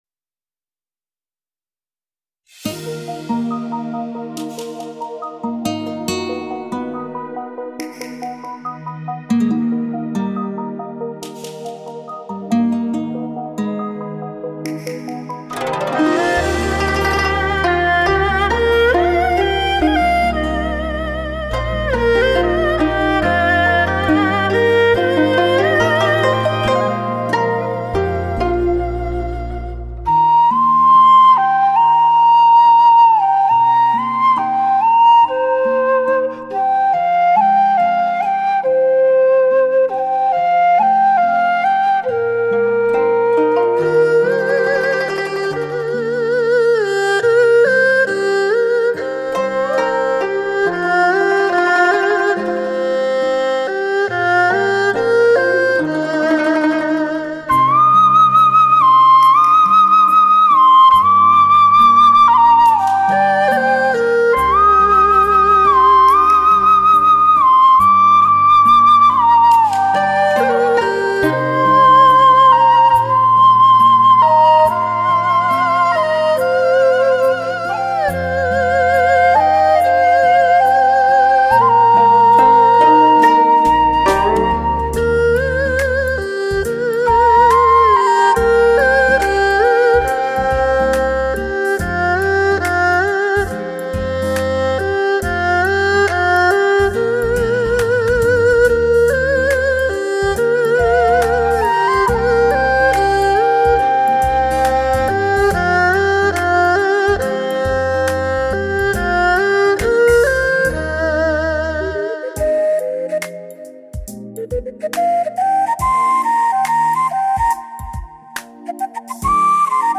HI-FI典范，如歌旋律 完美演奏，完美的录音浑然天成。质朴、自然、真实的录音，
音域宽广，却又细腻绵绵，音质清纯之美令人陶醉，营造出一个充满活力的HIFI场面，